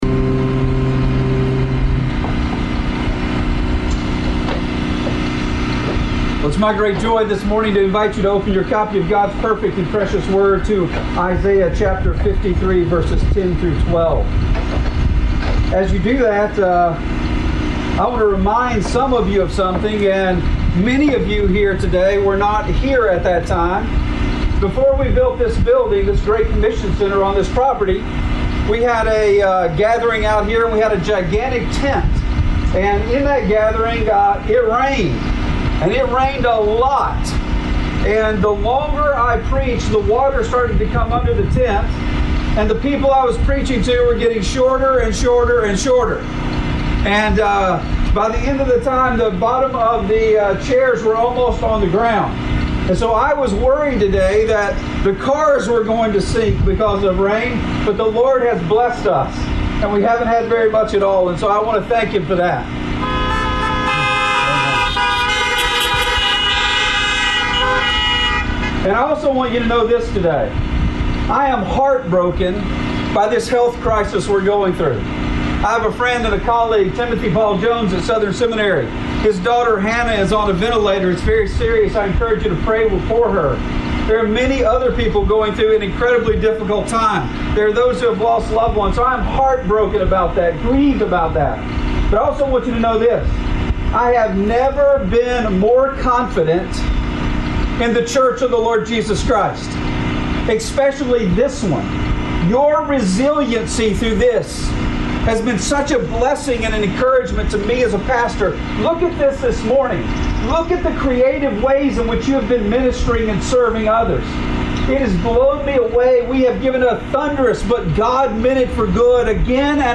Satisfied with His Suffering (Isaiah 53:10-12): An Easter Drive-In Message